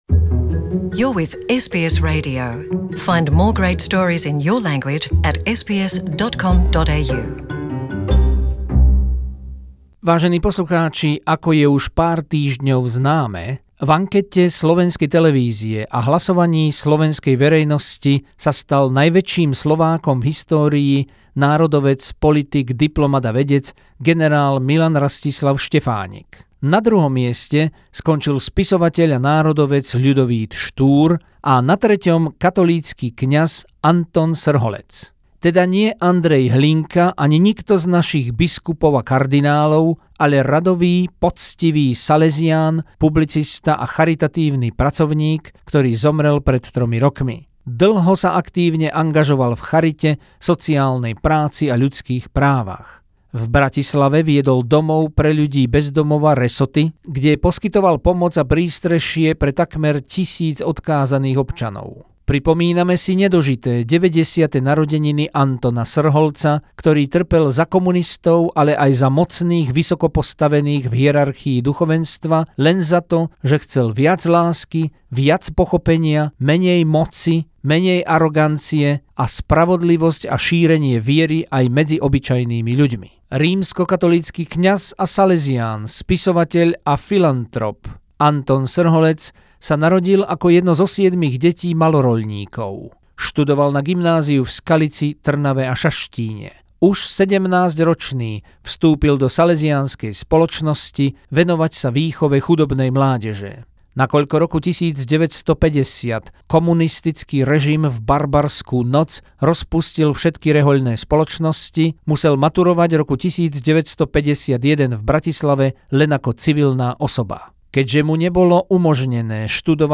Spomienka na tretieho najväčšieho Slováka v dejinách v ankete RTVS Antona Srholca, ktorý by sa bol dožil 90-tich rokov. Zaznejú aj jeho posledné slová z nemocnice pre slovenský národ.